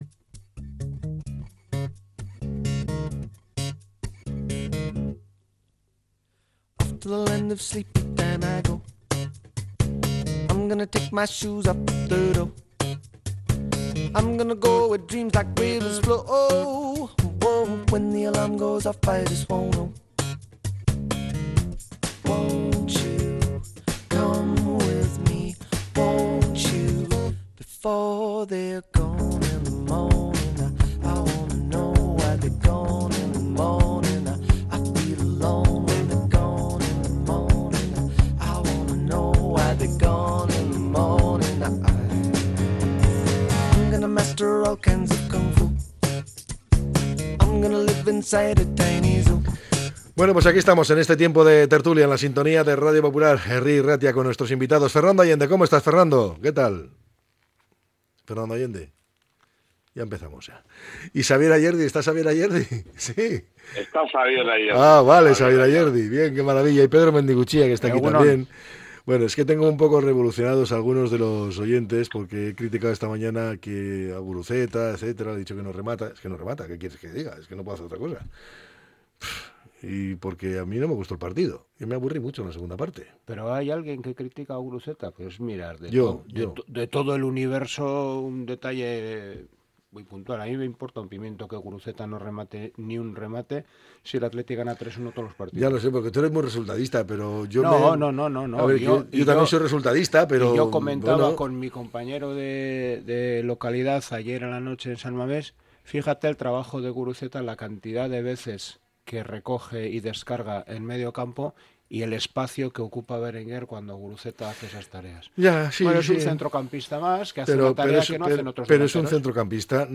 La tertulia 31-01-25.